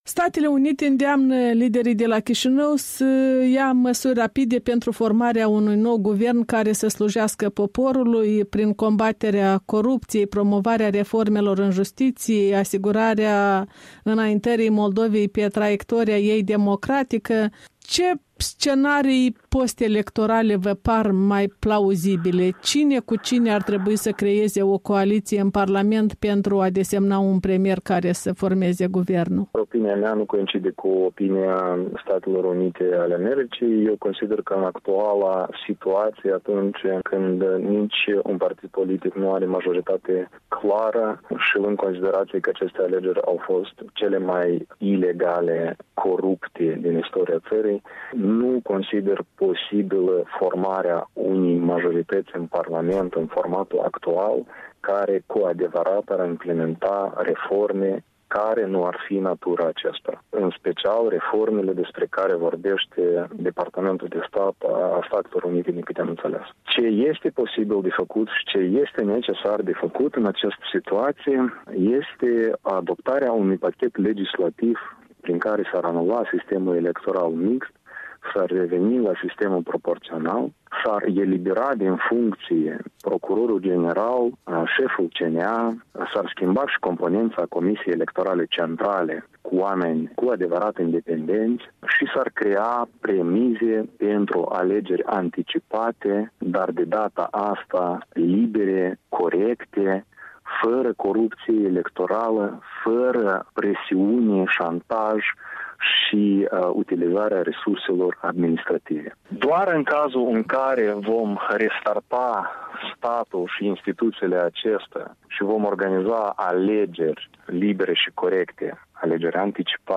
Un interviu cu un expert în politici publice.